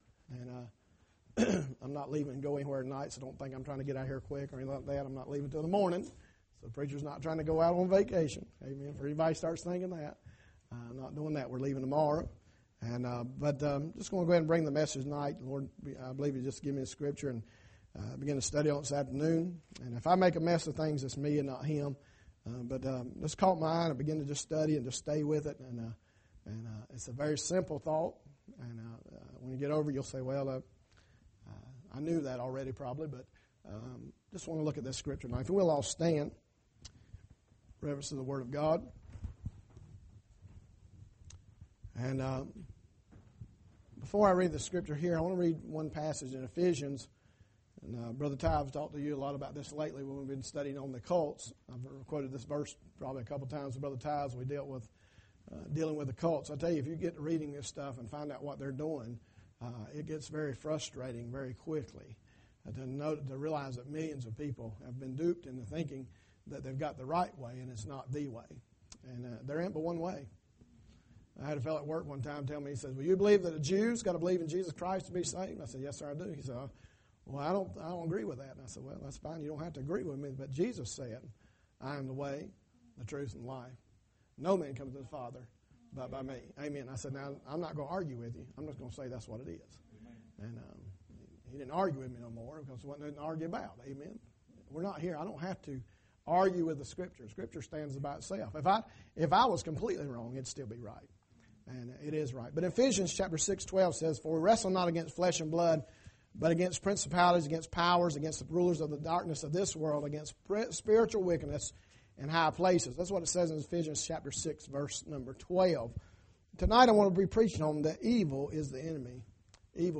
Maple Grove Baptist Church, Weddington NC